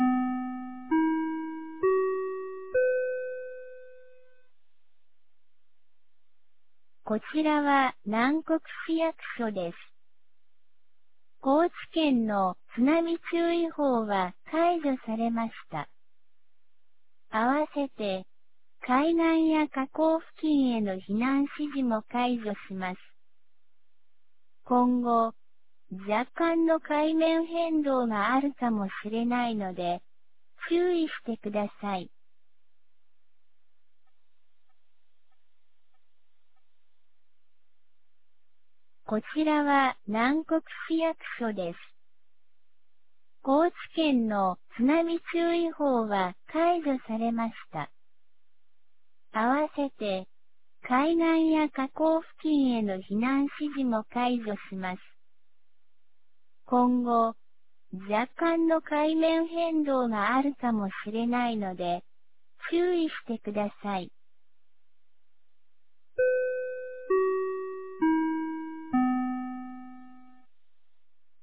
放送音声
2024年08月08日 19時25分に、南国市より放送がありました。